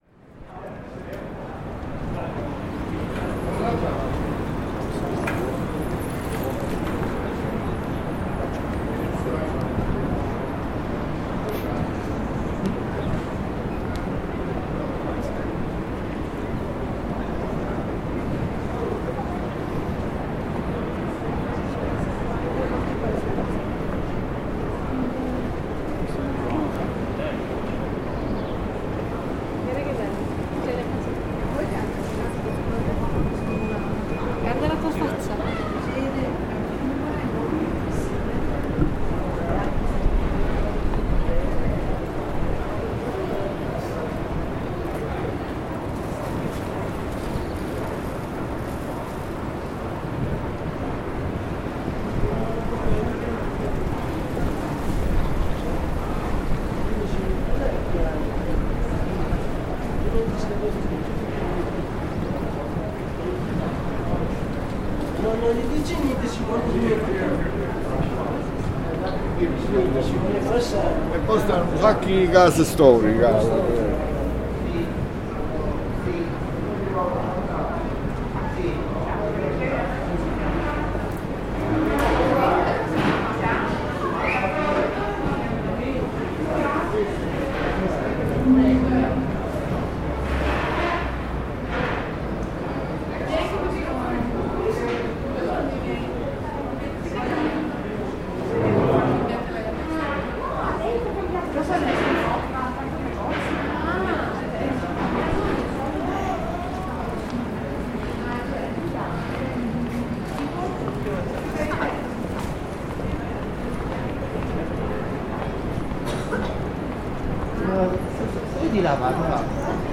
The sound of the famous Ponte degli Alpini in Bassano del Grappa, Italy.